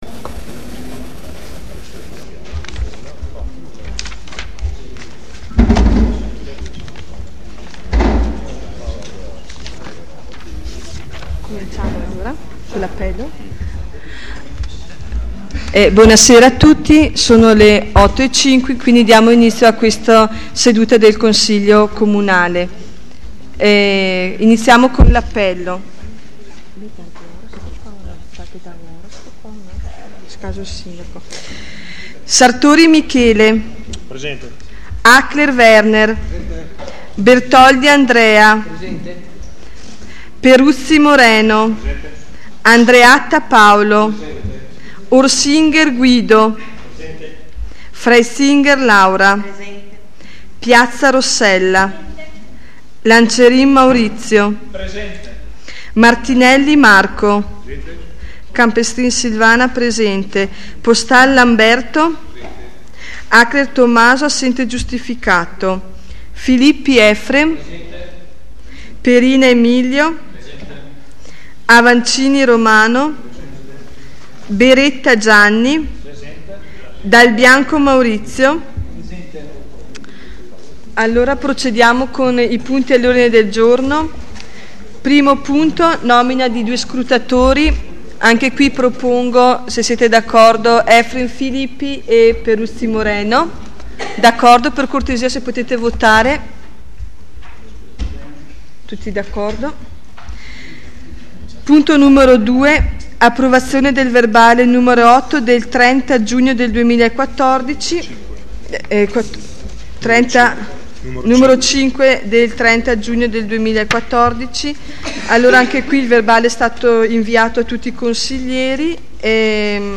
Seduta - data Venerdì, 28 Novembre 2014